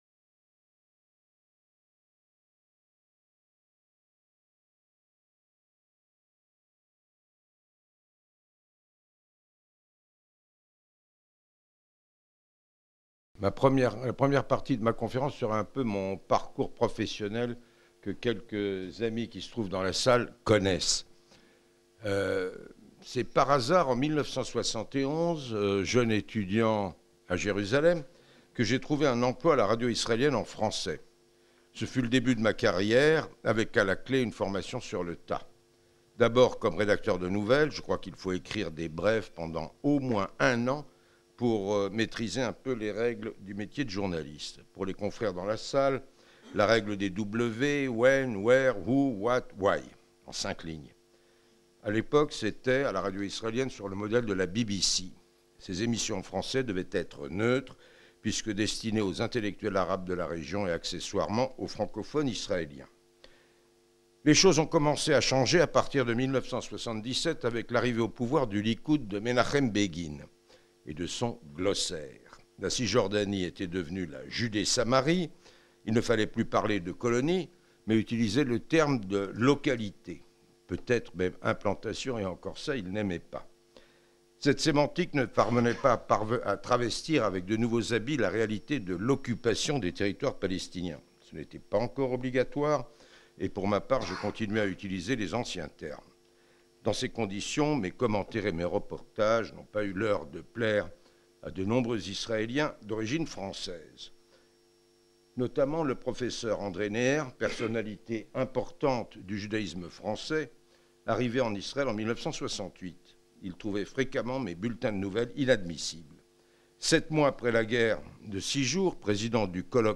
Conférences Penser global Correspondant de France Télévisions à Jérusalem de 1981 à 2015, Charles Enderlin a été le témoin privilégié de moments historiques du conflit israélo-palestinien.